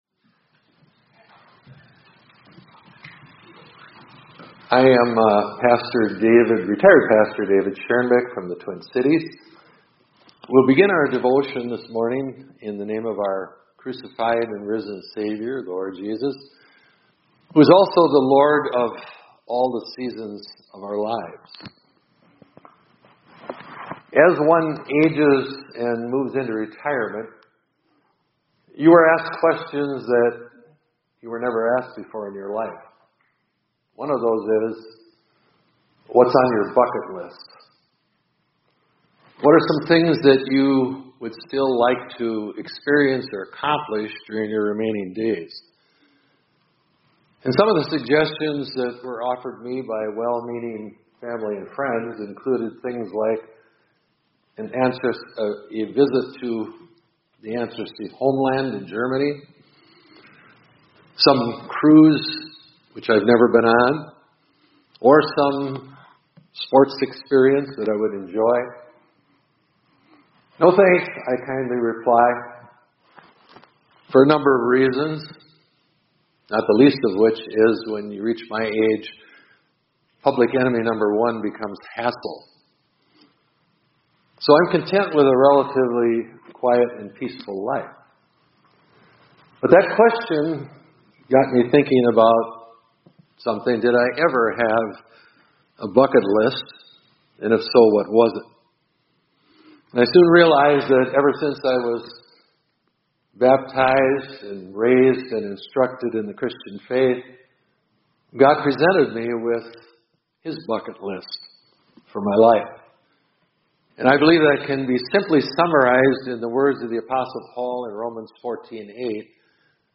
2025-04-29 ILC Chapel — God Has a Bucket List For You